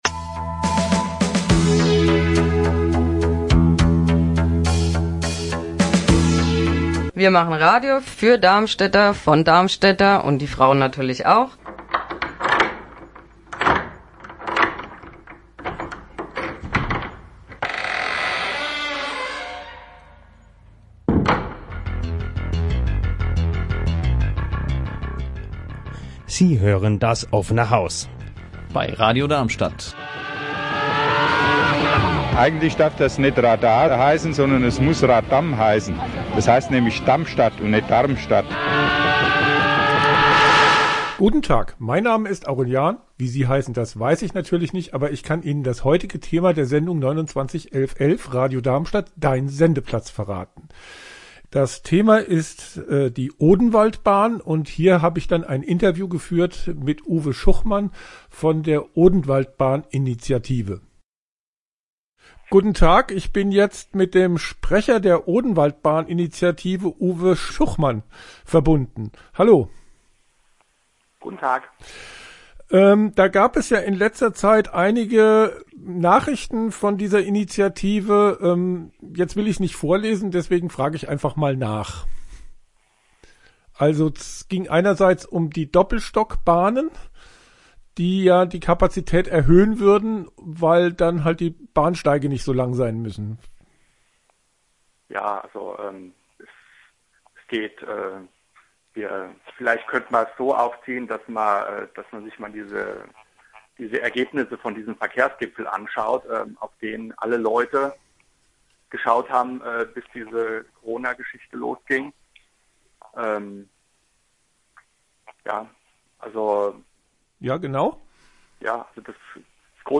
Im Interview bei Radio Darmstadt vom April 2020 sind weitere Gründe für die Normalpur im Ostkreis nachzuhören.